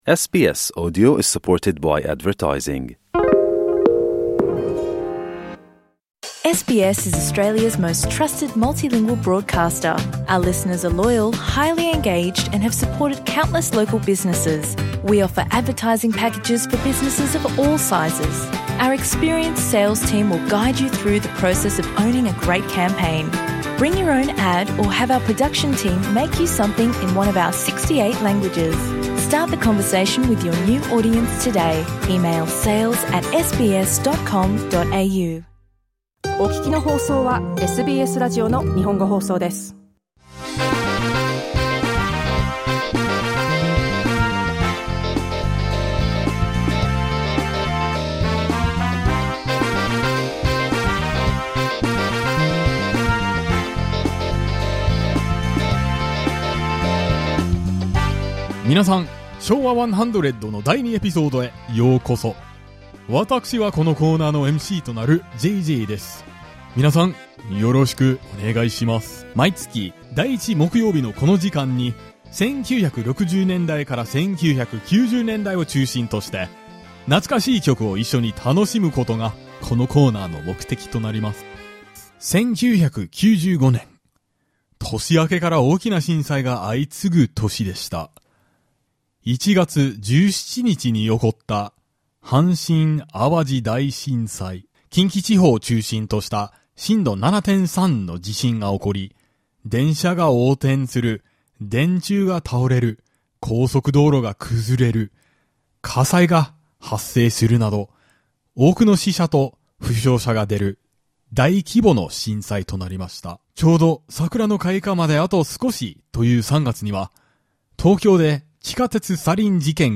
If the Showa era in Japan continues, this year marks the centenary of the Showa period. Let's look back on that era while enjoying nostalgic songs from the 1960s to the 1990s.